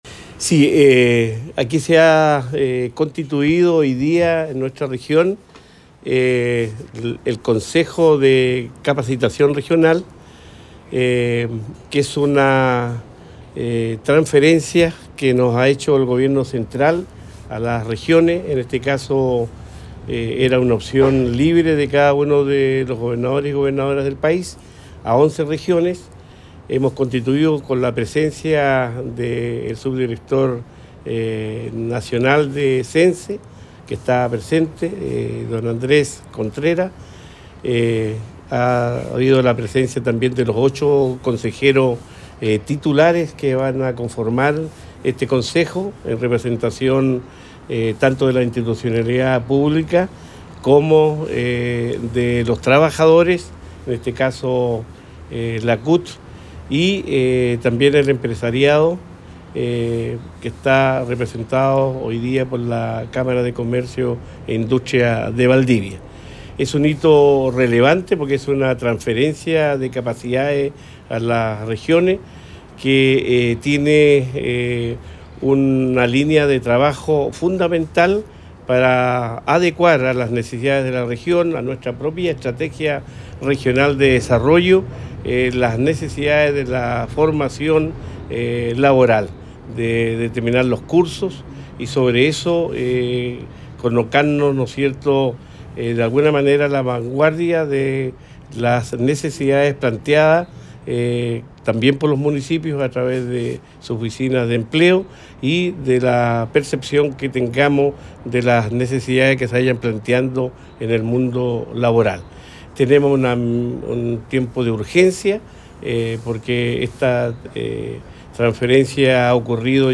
Cuña_Gobernador_Consejo-Regional-de-Capacitación.mp3